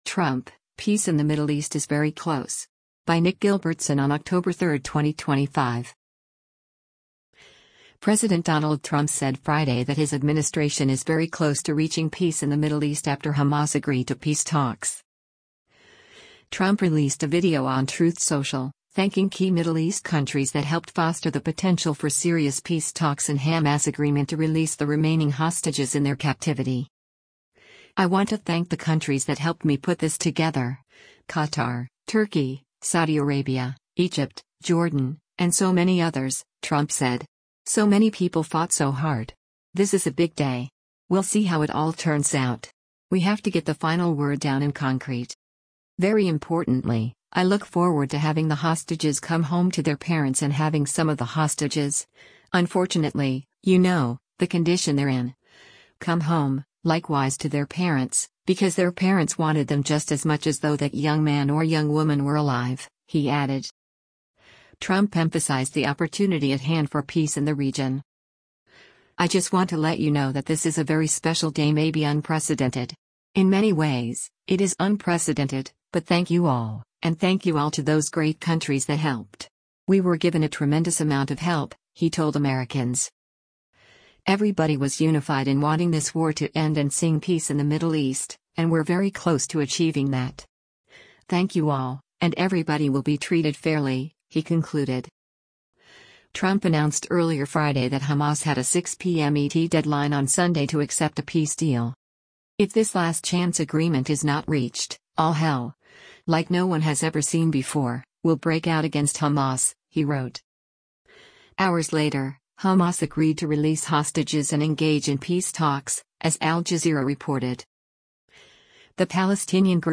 Trump released a video on Truth Social, thanking key Middle East countries that helped foster the potential for serious peace talks and Hamas’s agreement to release the remaining hostages in their captivity.